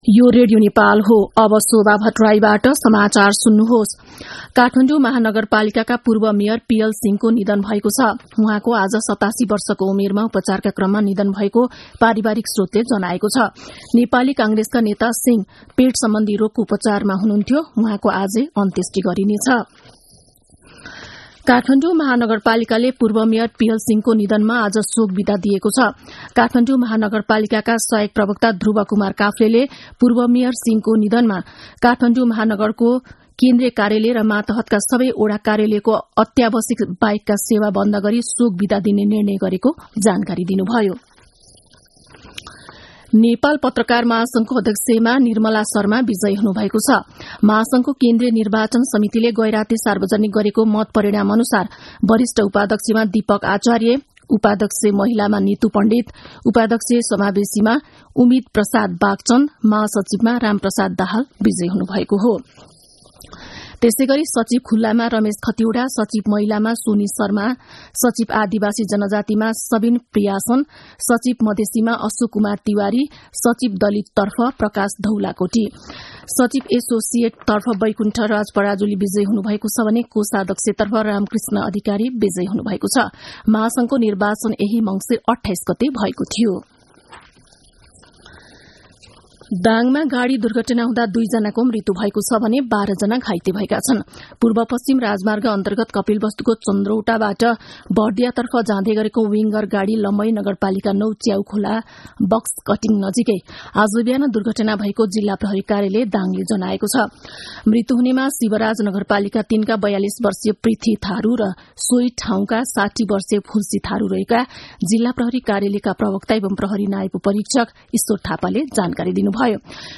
An online outlet of Nepal's national radio broadcaster
दिउँसो १ बजेको नेपाली समाचार : २ पुष , २०८१
1-pm-nepali-news-1-12.mp3